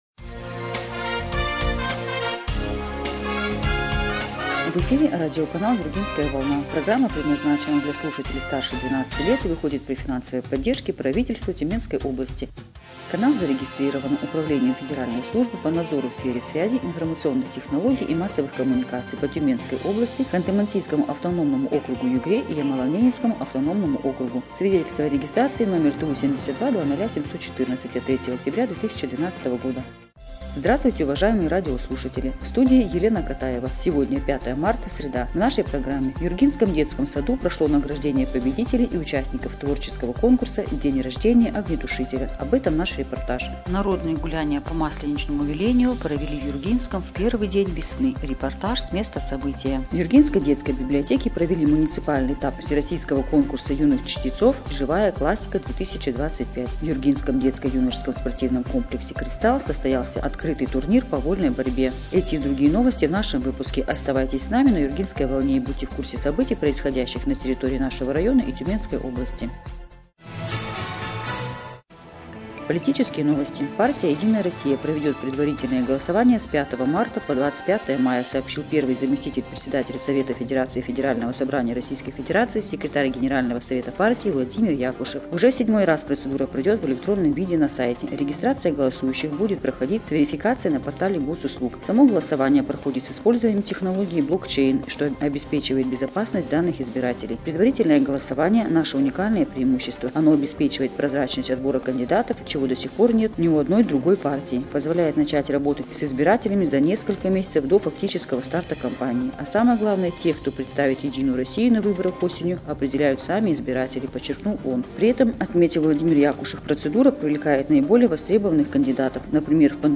Эфир радиопрограммы "Юргинская волна" от 5 марта 2025 года